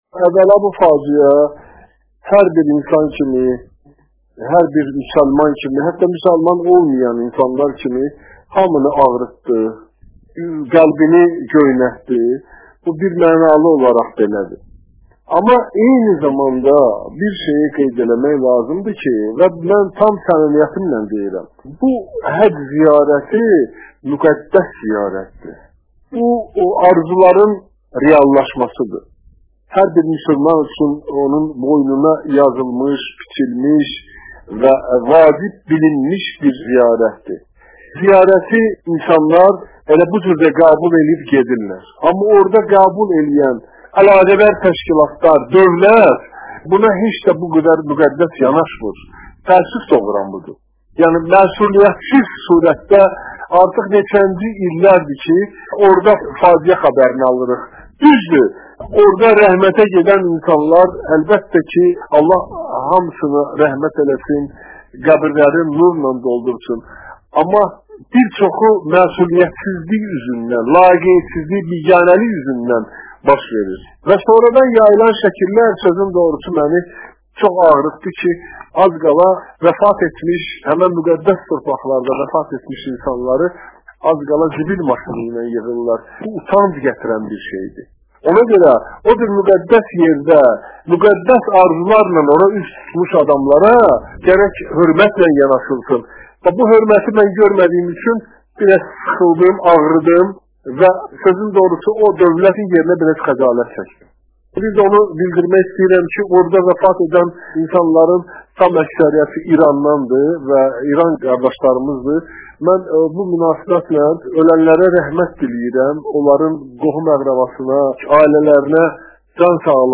Beynəlxalq Səhər Telekanalının azəri radiosu ilə müsahibədə